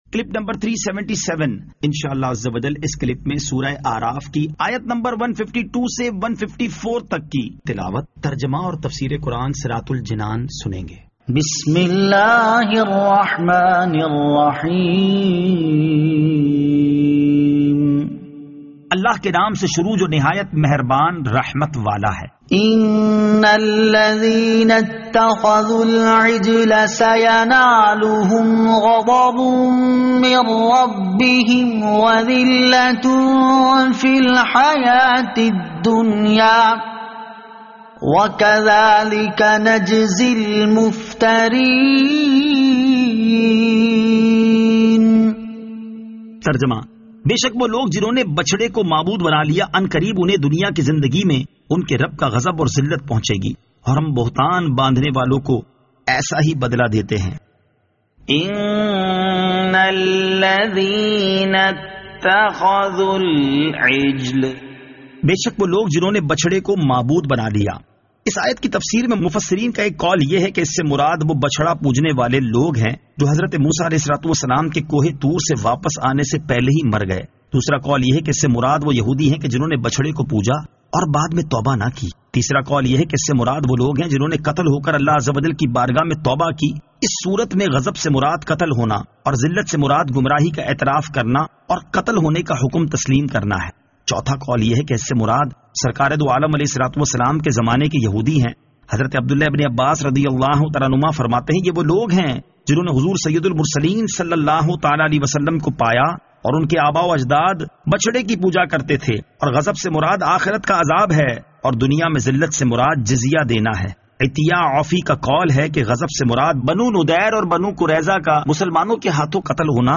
Surah Al-A'raf Ayat 152 To 154 Tilawat , Tarjama , Tafseer